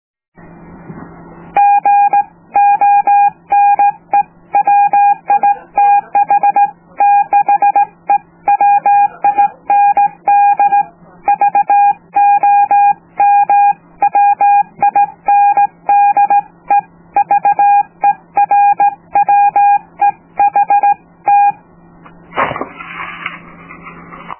example for morse-code with a handkey